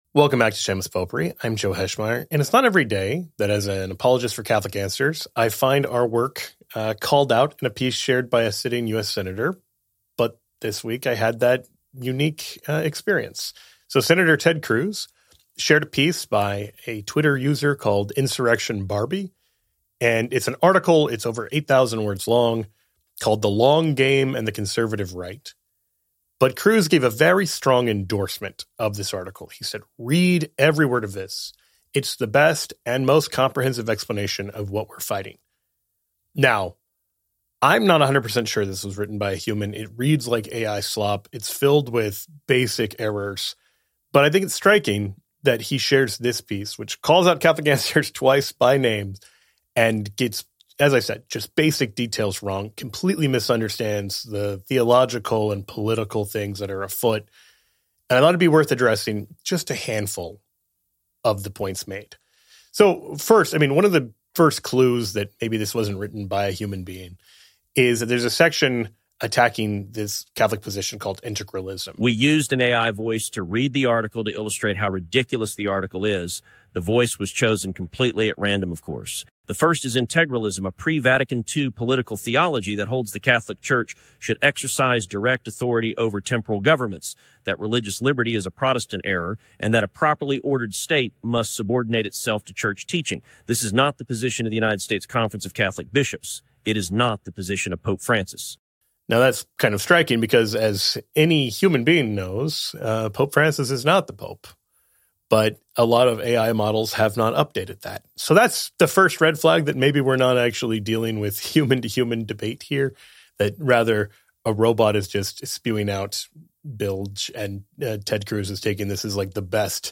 Not Ted: We used an AI voice to read the article to illustrate how ridiculous the article is.